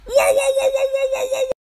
Play, download and share woaoaooaooa original sound button!!!!
woahwoahwoahwoah_3XB7OmO.mp3